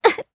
Laudia-Vox_Damage_jp_02.wav